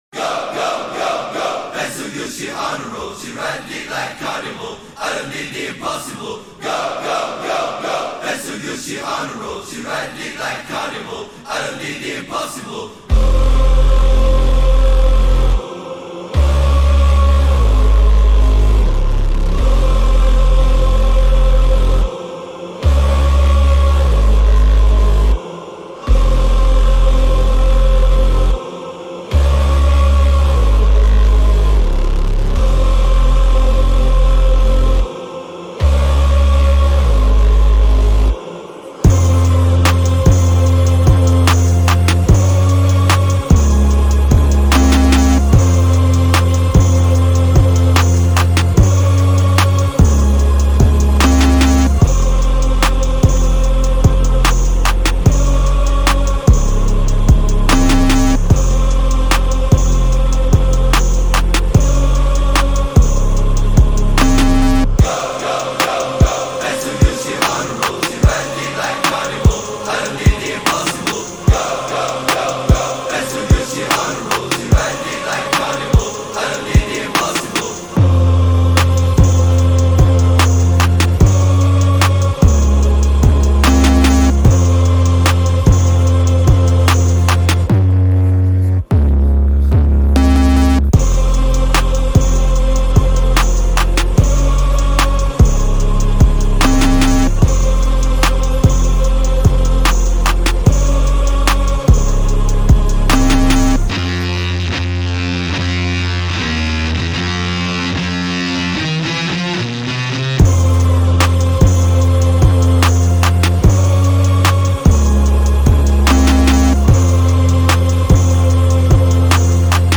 Trap Ano de Lançamento